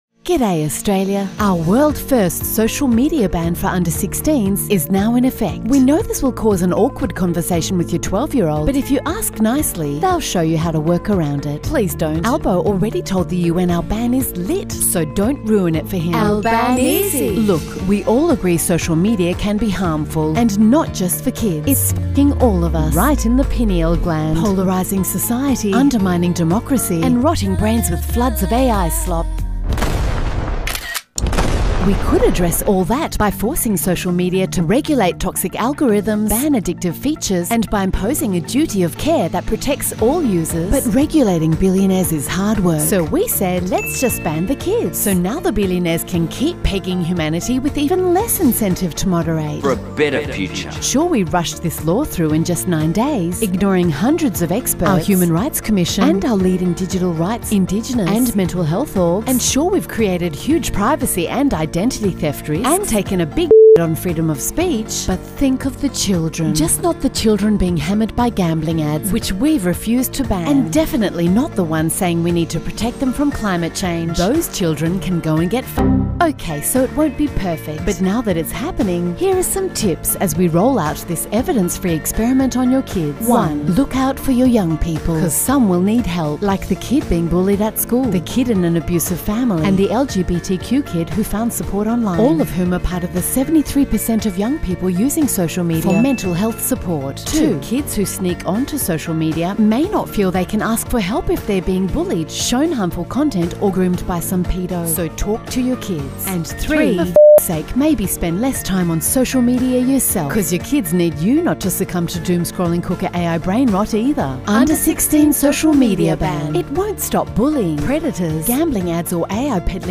(Expurgated- radioplay)